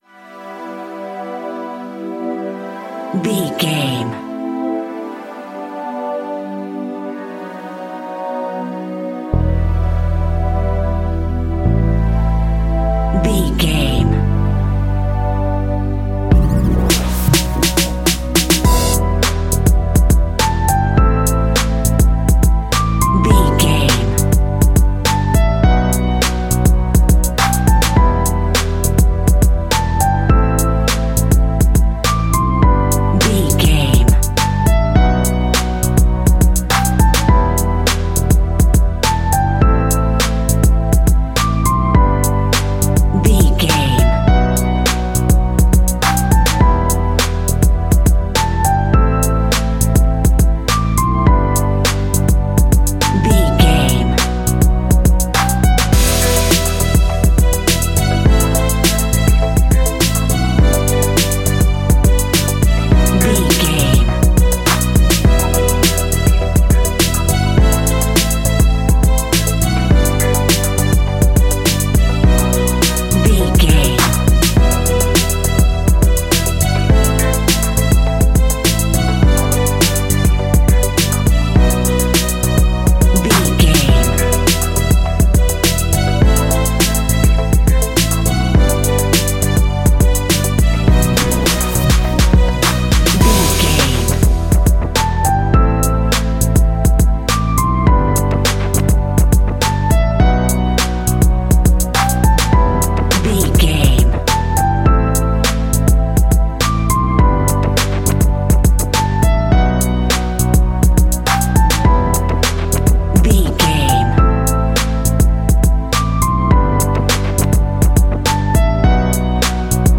Aeolian/Minor
hip hop instrumentals
downtempo
synth lead
synth bass
synth drums
turntables